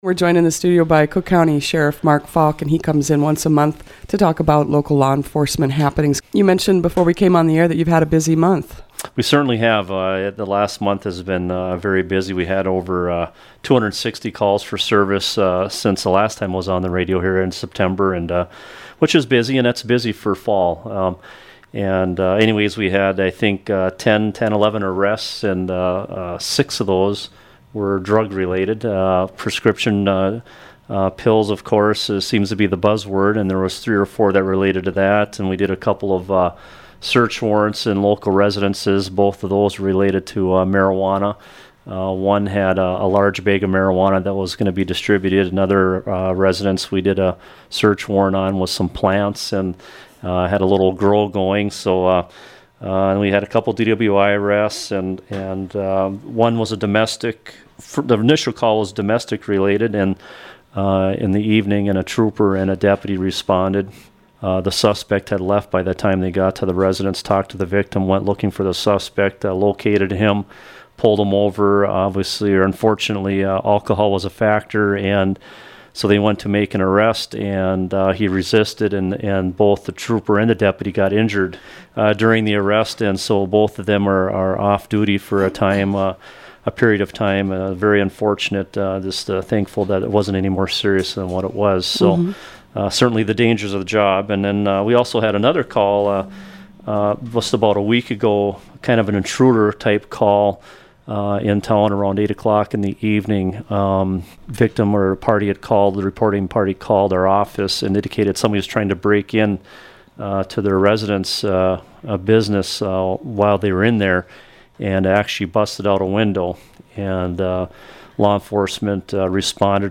Cook County Sheriff Mark Falk visits the WTIP studios once a month to discuss law enforcement efforts.